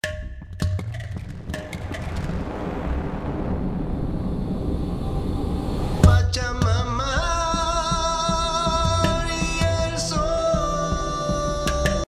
Zum Übergang vom Intro zum ersten Gesangspart: Hier füge ich noch einen Takt ein, um dem Wechsel mehr Zeit zu lassen, tausche den Woosh durch einen anderen Sound aus Rise & Hit aus (metallenes Geräusch gegen Chöre) und verändere auch beim GRM Shuffling Delay, welches an dieser Stelle die indische Percussion in einen Effektsound verwandelt, die Tonhöhenmodulation so, dass es nicht mehr ganz so schrill abhebt.